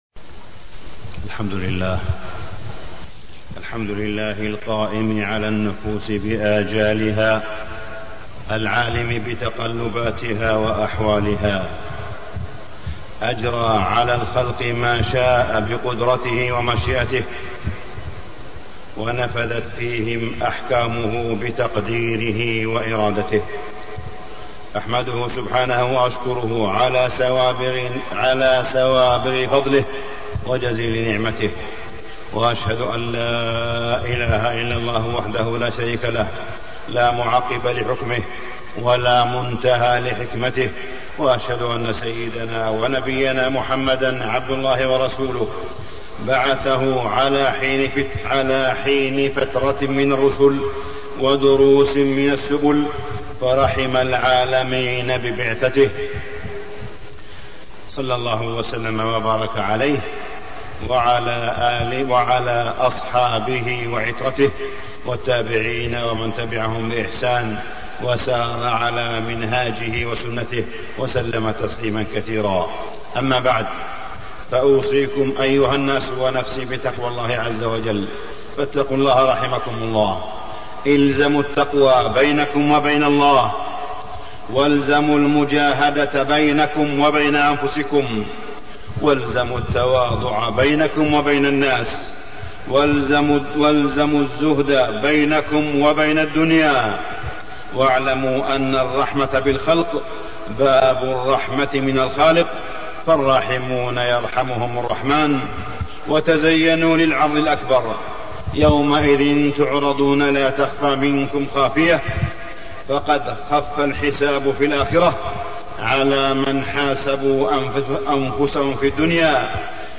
تاريخ النشر ١٩ ربيع الأول ١٤٣١ هـ المكان: المسجد الحرام الشيخ: معالي الشيخ أ.د. صالح بن عبدالله بن حميد معالي الشيخ أ.د. صالح بن عبدالله بن حميد حسن الخلق